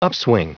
Prononciation du mot upswing en anglais (fichier audio)
Prononciation du mot : upswing